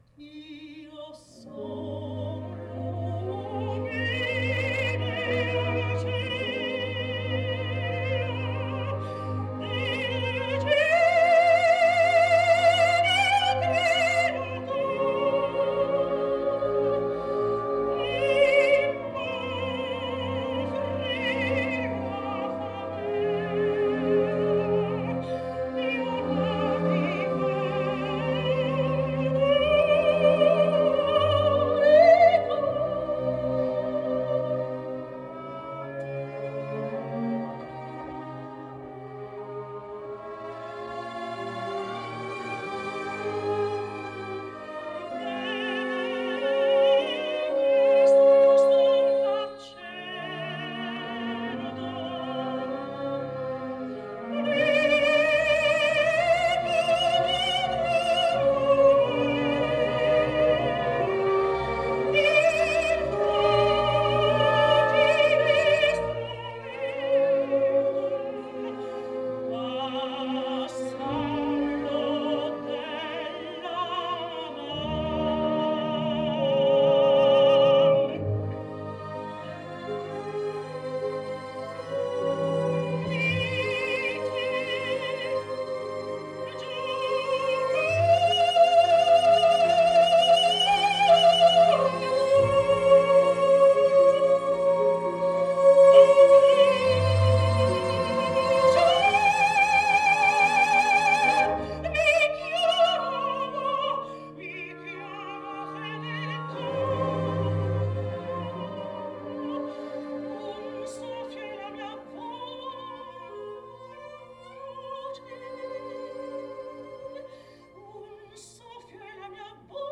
111 лет со дня рождения итальянской певицы (сопрано) Мафальды Фаверо (Mafalda Favero)